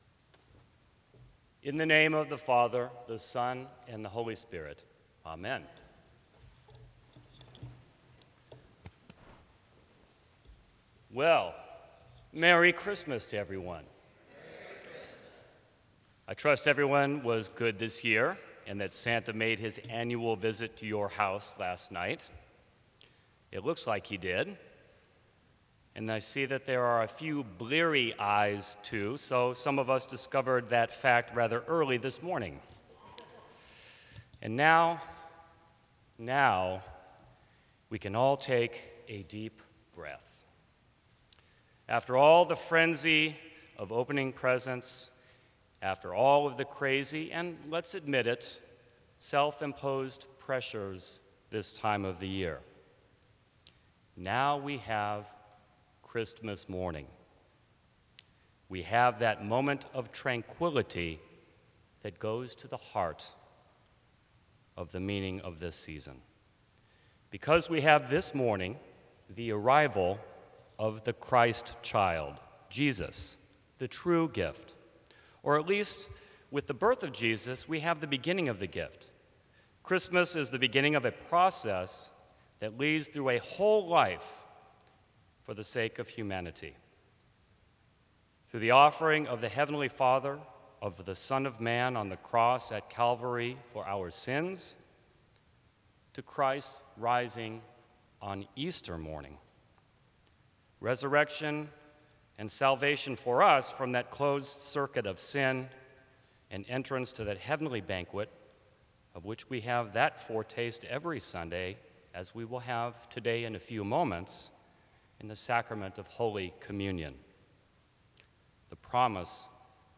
Sermons from St. Cross Episcopal Church Christmas Day 2013 Dec 27 2013 | 00:13:46 1x 00:00 / 00:13:46 Subscribe Share Apple Podcasts Spotify Overcast RSS Feed Share Link Embed